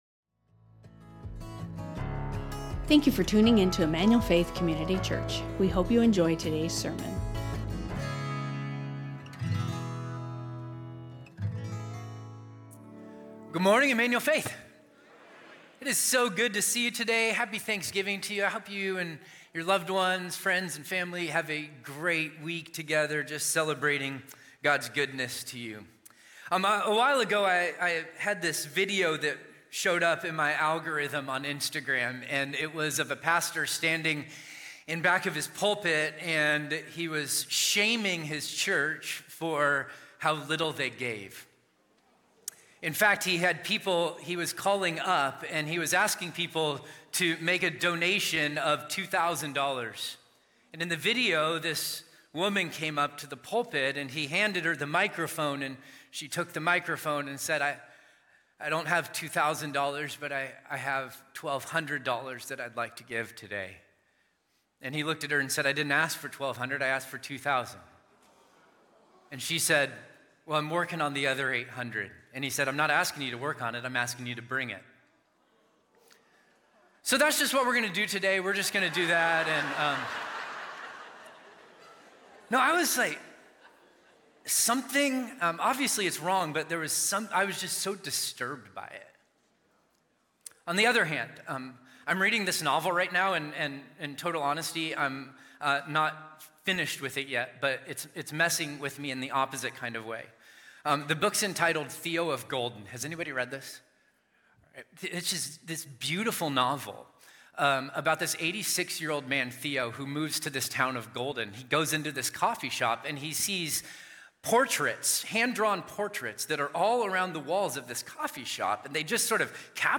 Emmanuel Faith Sermon Podcast Free-flowing Generosity | 2 Corinthians 9:1-15 Nov 24 2025 | 00:42:18 1x 00:00 / 00:42:18 Subscribe Share Spotify Amazon Music RSS Feed Share Link Embed